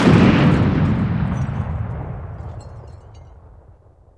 Index of /90_sSampleCDs/AKAI S6000 CD-ROM - Volume 1/SOUND_EFFECT/EXPLOSIONS